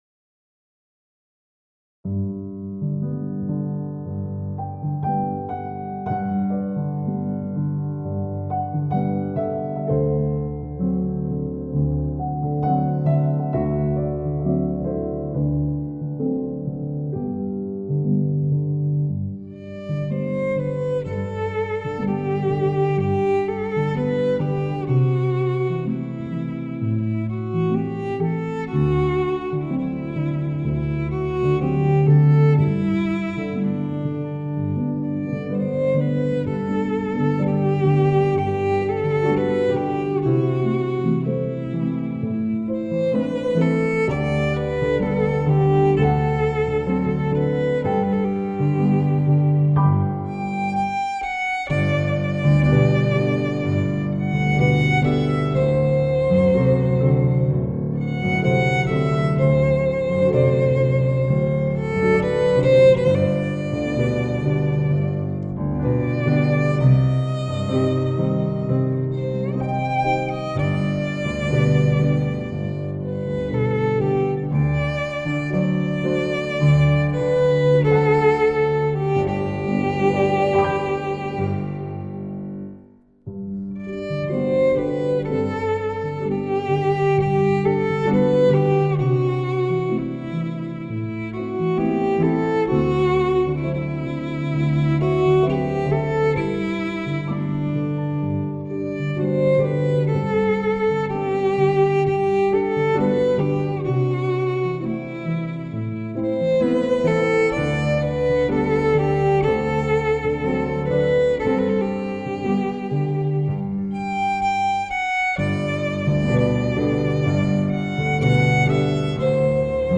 바이얼린 연주곡